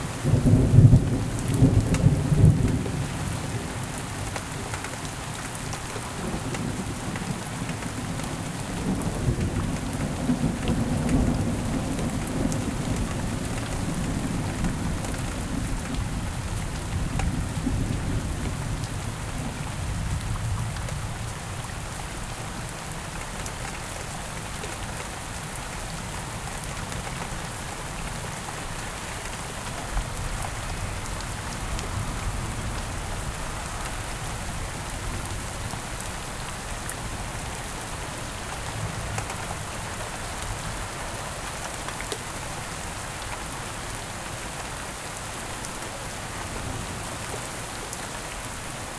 rain_with_littel_thunder_loop.ogg